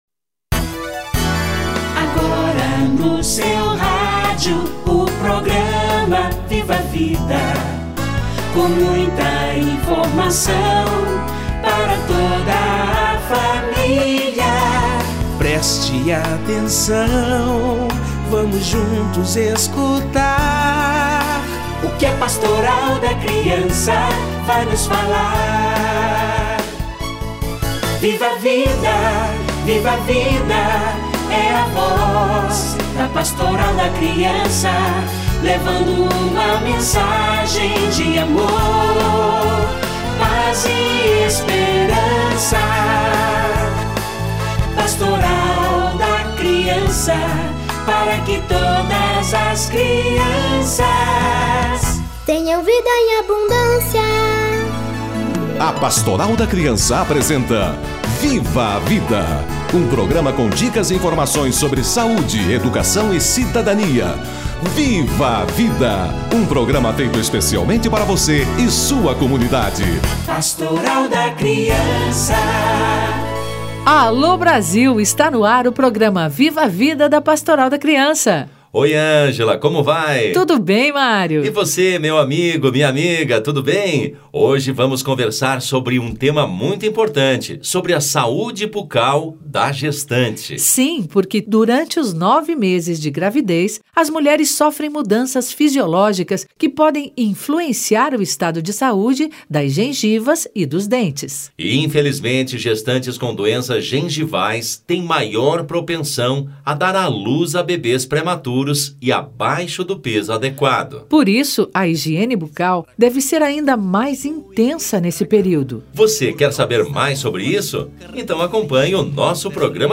Saúde Bucal da Gestante - Entrevista